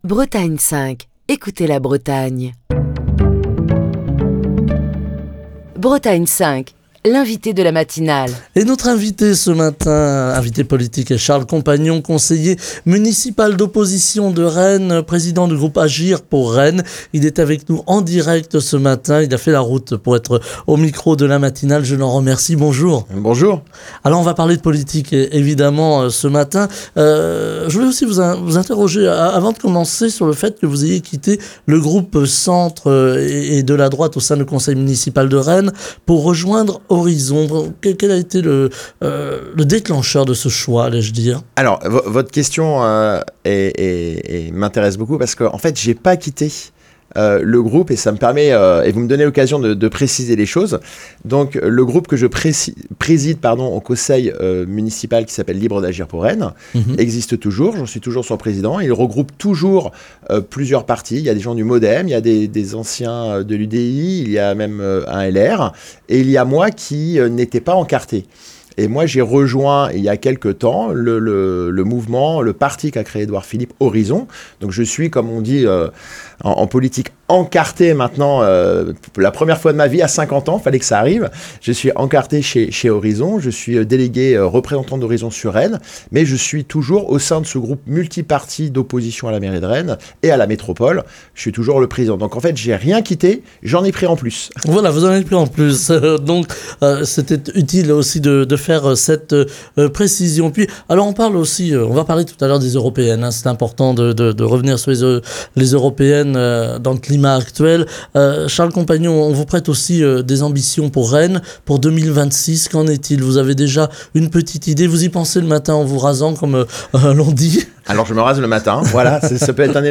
Ce vendredi, nous parlons de Rennes avec Charles Compagnon, conseiller municipal d'opposition de Rennes, président du groupe Libres d'Agir pour Rennes est l'invité politique de Bretagne 5 Matin.